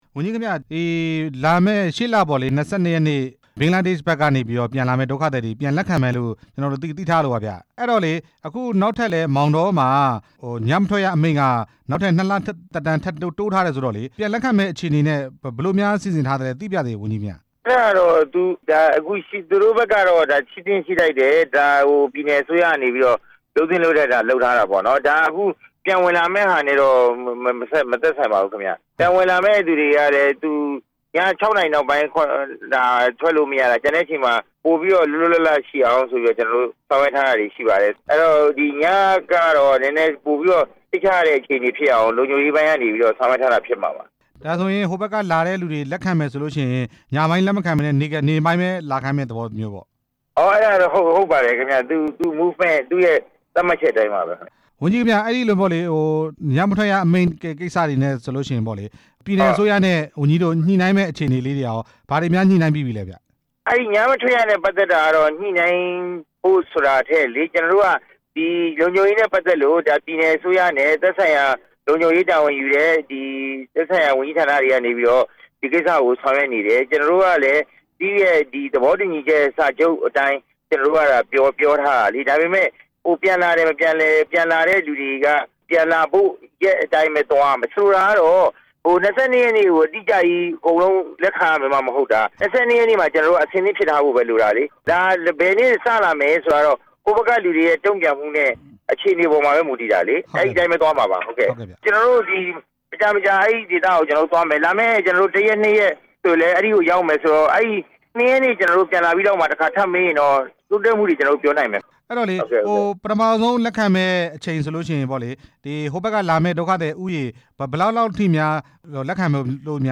ဒုက္ခသည်ပြန်လည်လက်ခံရေး ဆက်သွယ်မေးမြန်းချက်